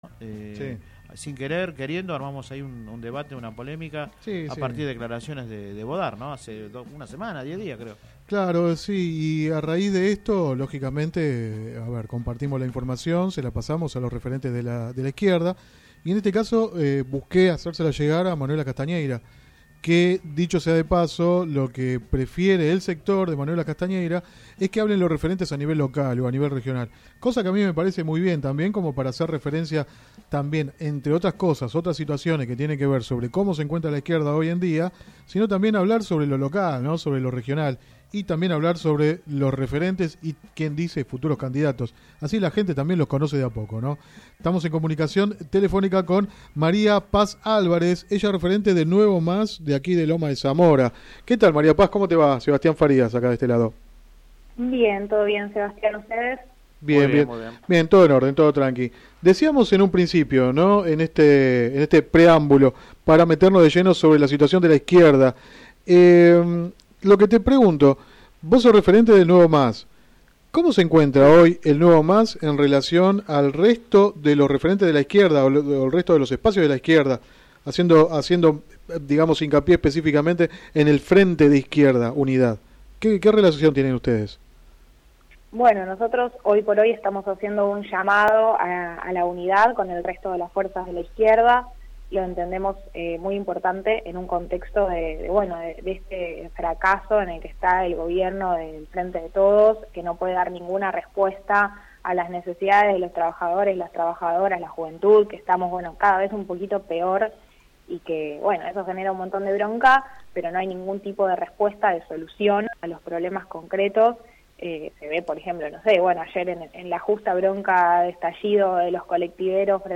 Click acá entrevista radial